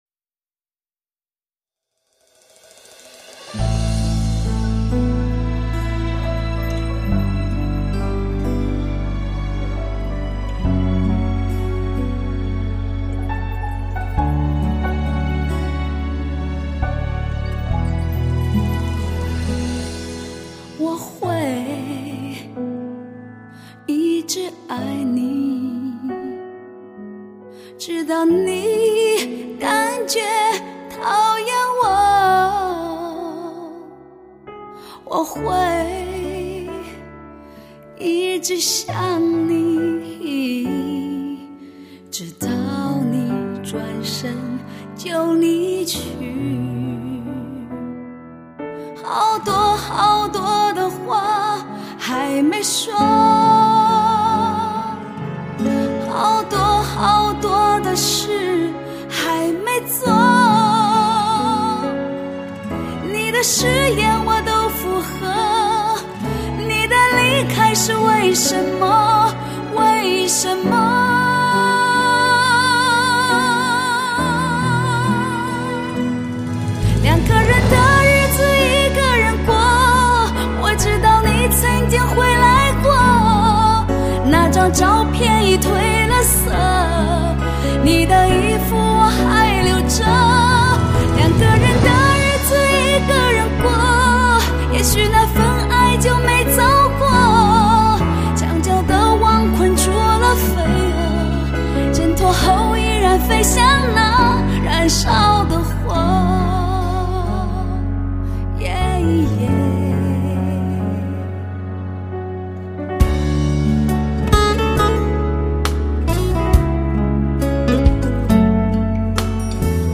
经典情歌，百听不厌，熟悉的旋律，给你最深处的感动。